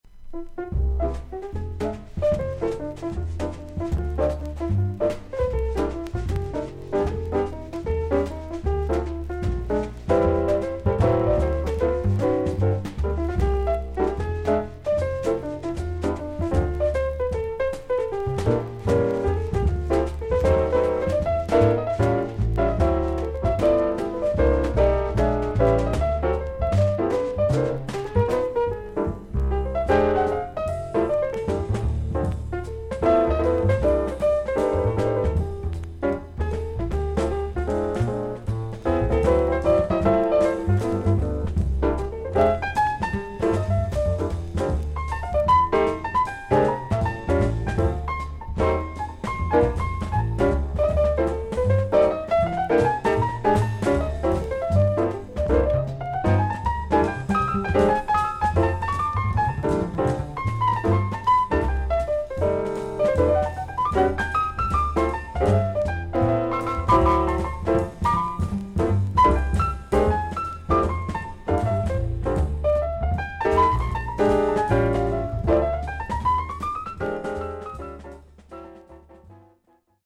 少々軽いパチノイズの箇所あり。少々サーフィス・ノイズあり。クリアな音です。
ジャズ・ピアニスト。
リラックスした雰囲気でスタンダード・ナンバーを演奏しています。